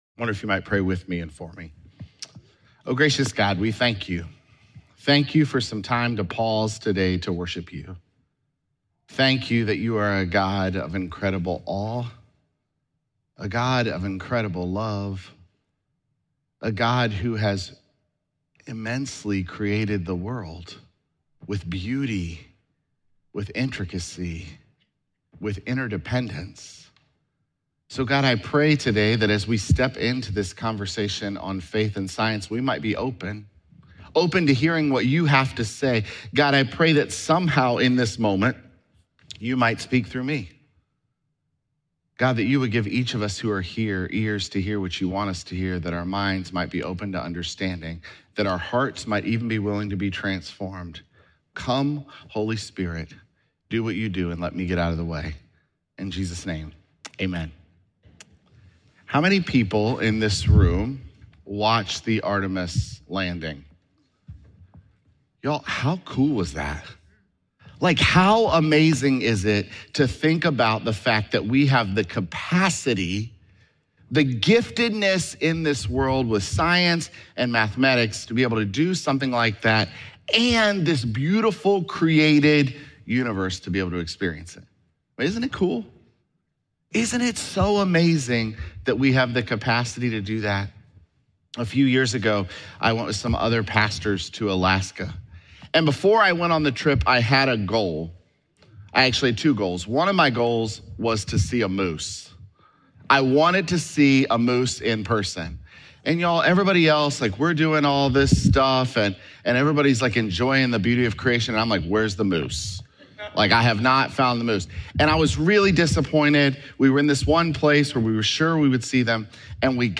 Sermons
Apr12SermonPodcast.mp3